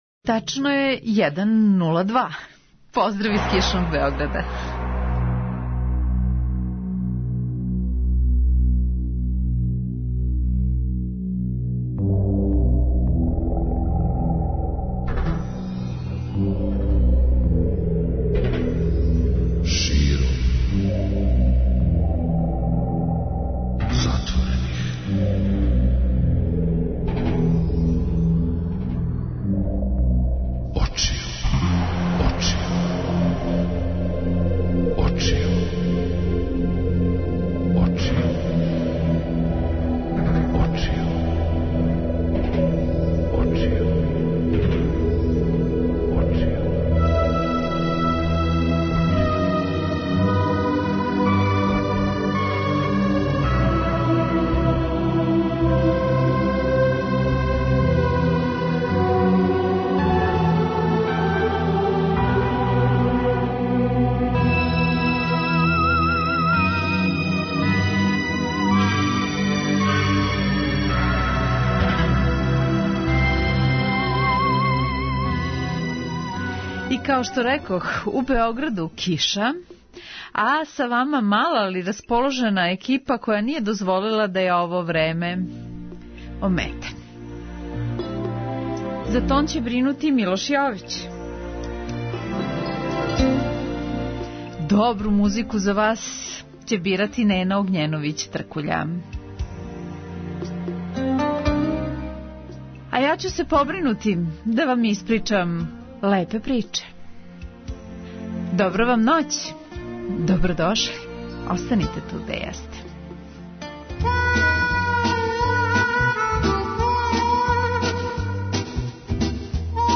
Такође ће слушаоци имати прилике да чују и неке старе добре композиције које у свакоме од нас изазивају сету.